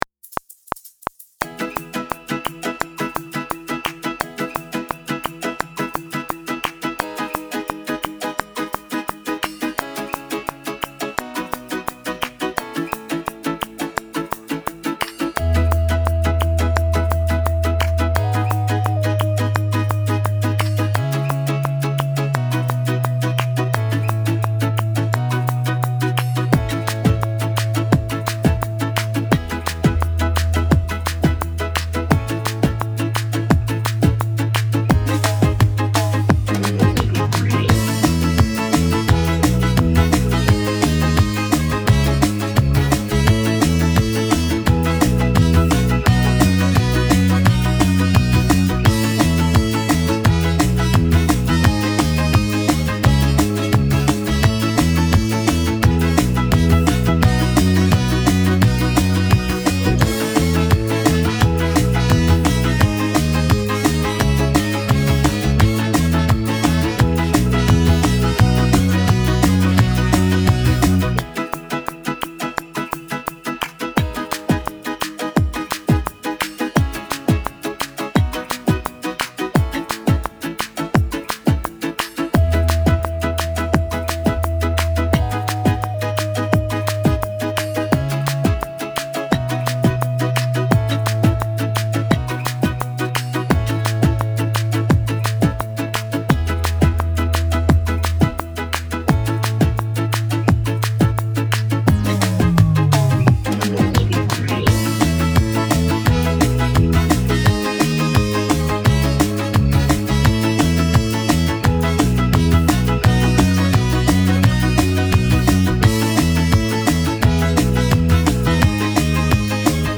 Base instrumental